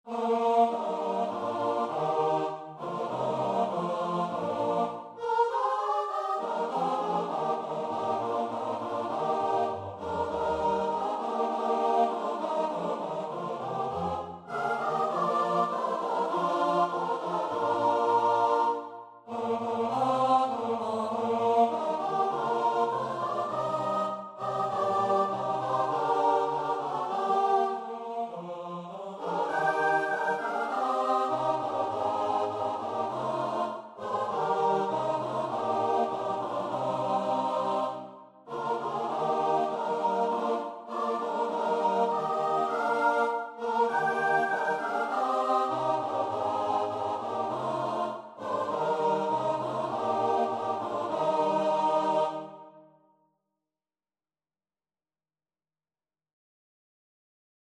Free Sheet music for Choir (SATB)
Eb major (Sounding Pitch) (View more Eb major Music for Choir )
4/4 (View more 4/4 Music)
Choir  (View more Easy Choir Music)
Classical (View more Classical Choir Music)
iceland_nat_CH.mp3